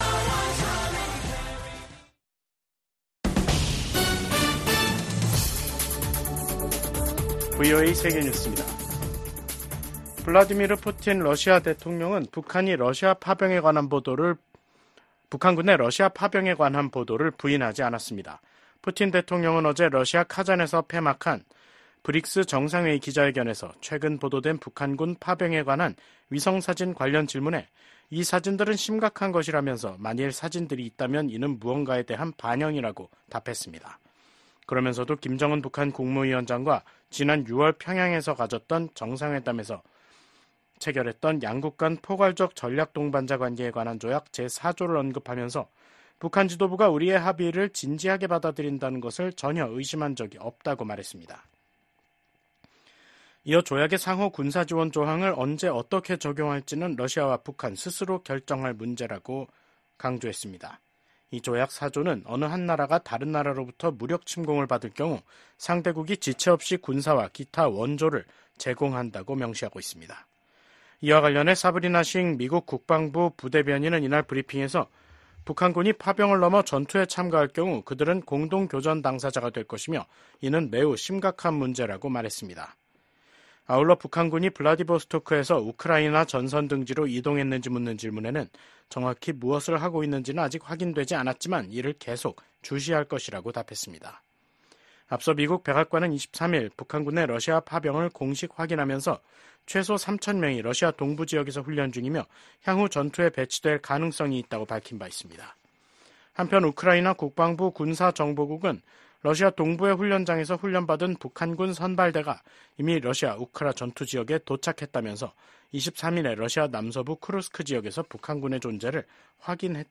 VOA 한국어 간판 뉴스 프로그램 '뉴스 투데이', 2024년 10월 25일 3부 방송입니다. 미국 국방부는 러시아에 파병된 북한군이 우크라이나에서 전쟁에 참여할 경우 러시아와 함께 공동 교전국이 될 것이라고 경고했습니다. 미국 하원 정보위원장이 북한군의 러시아 파병과 관련해 강경한 대응을 촉구했습니다.